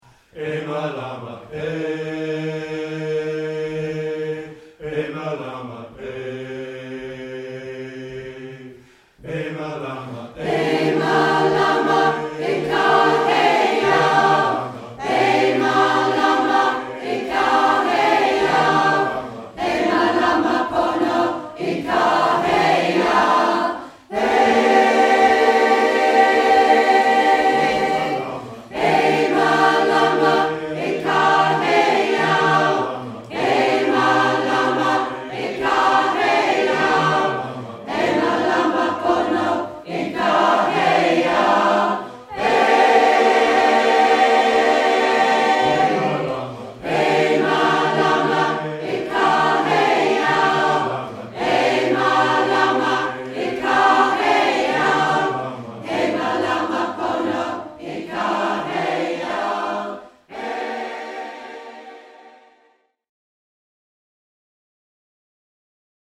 Langport Community Choir singing E Malama E, a contemporary Hawaiian spiritual song